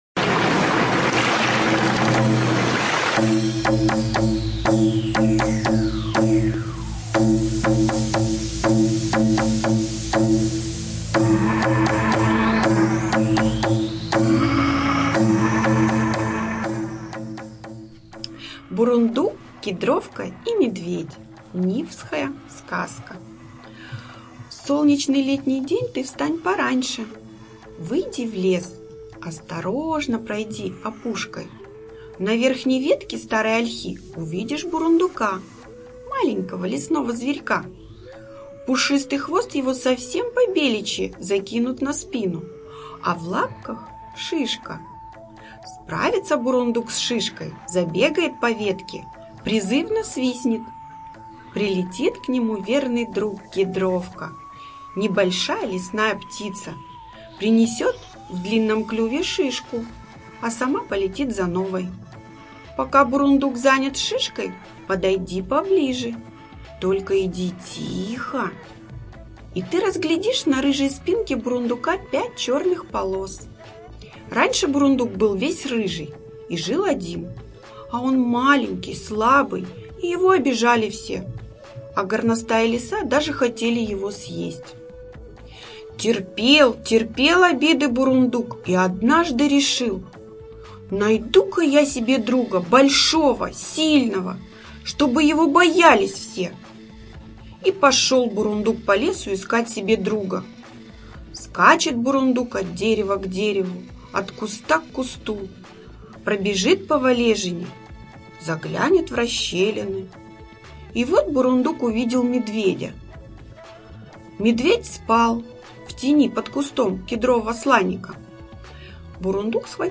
ЖанрСказки
Студия звукозаписиНижневартовская центральная городская библиотека